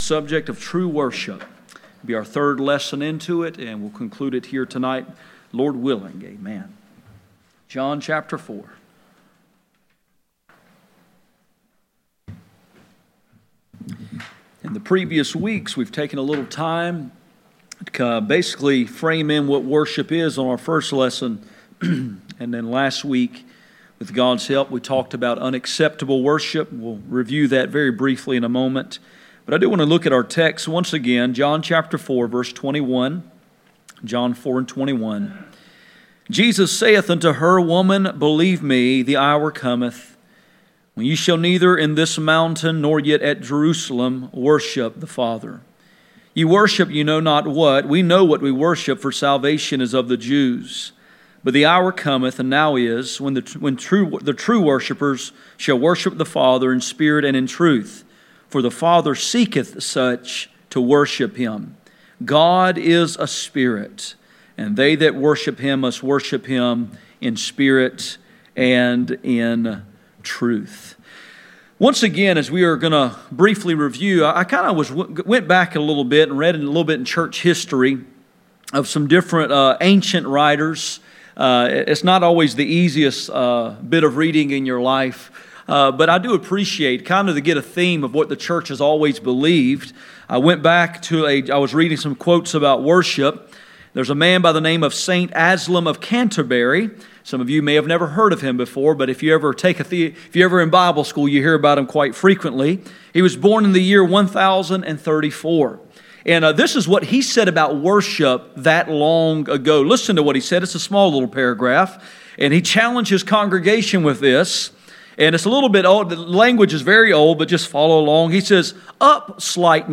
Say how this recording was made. None Passage: John 4:20-24 Service Type: Midweek Meeting %todo_render% « Pursuing the mind of Christ